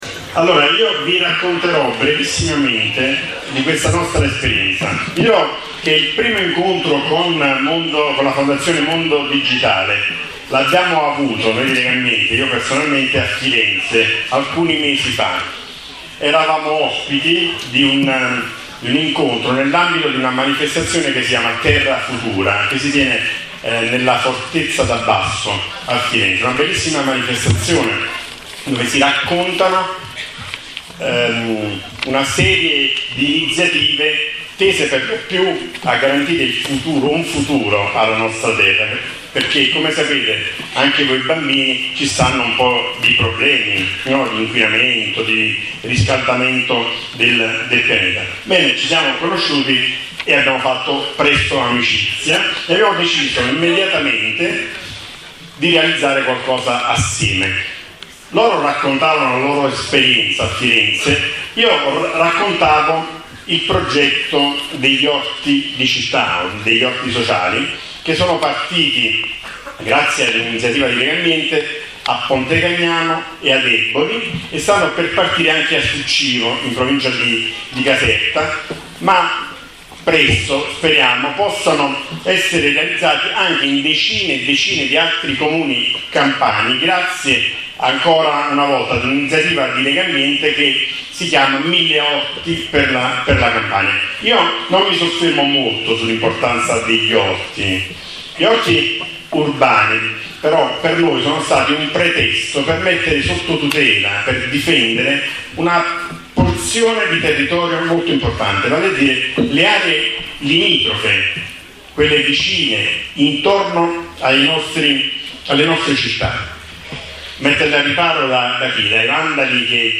Ieri a Pontecagnano è stato presentato il progetto Nonnet, orti urbani digitali.